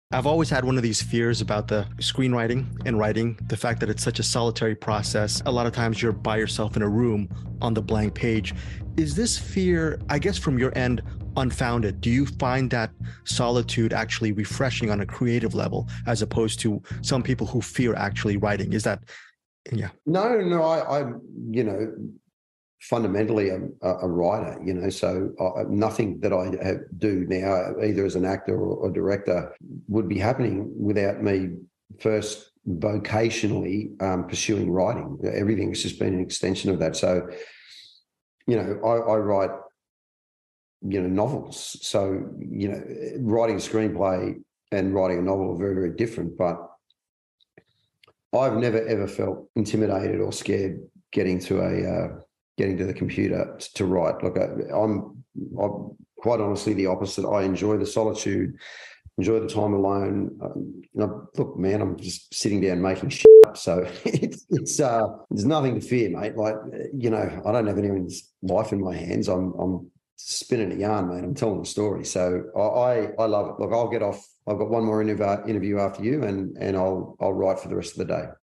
Transfusion director/writer/actor Matt Nable on enjoying the “solitude” of writing